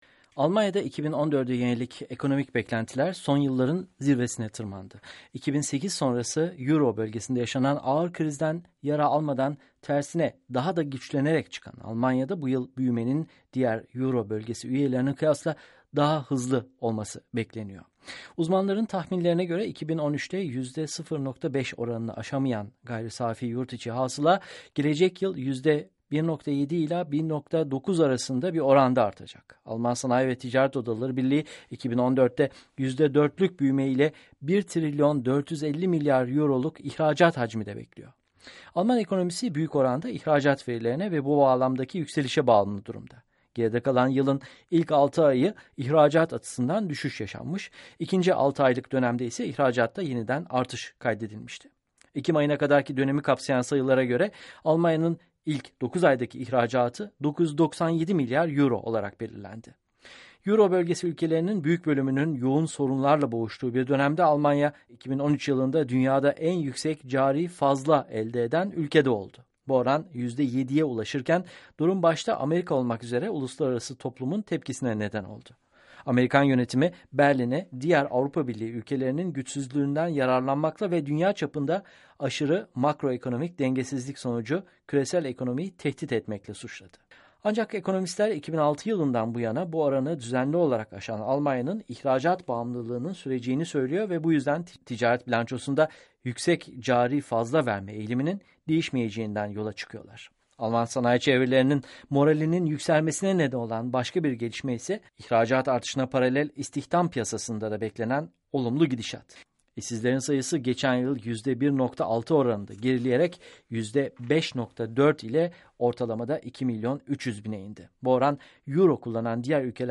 Haberi